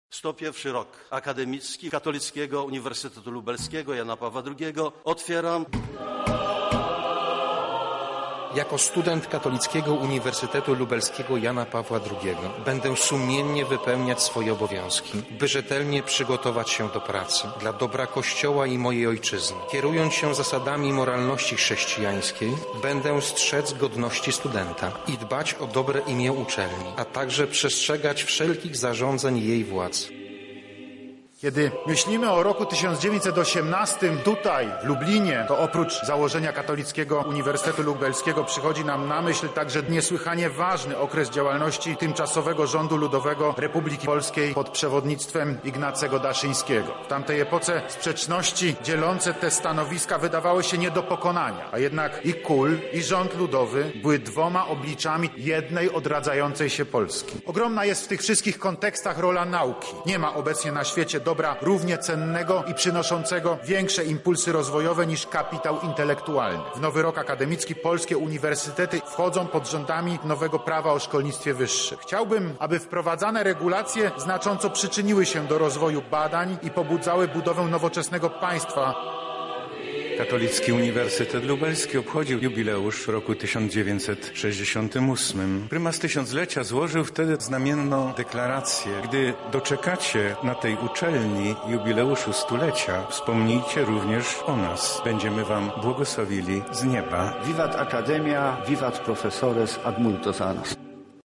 Ponadto w niedziele został zainaugurowany uroczyście 101 rok akademicki na Katolickim Uniwersytecie Lubelskim.